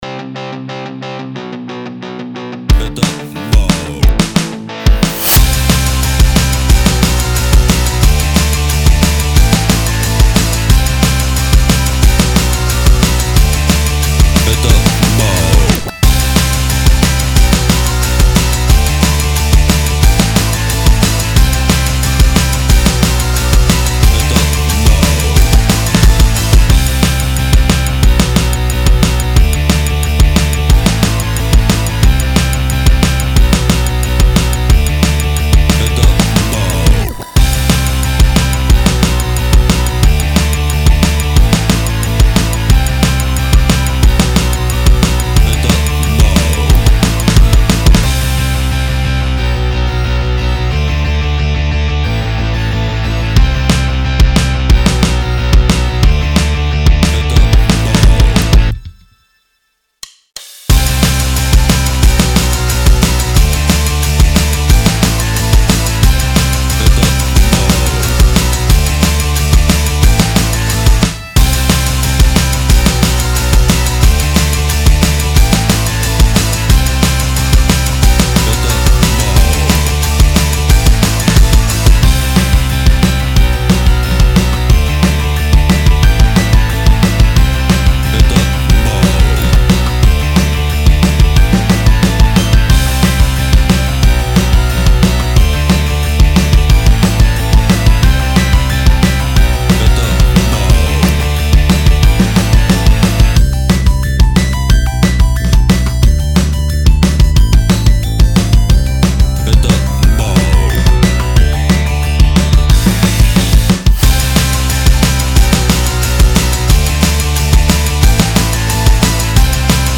Жанр: Hip-Hop
Rock Веселый 180 BPM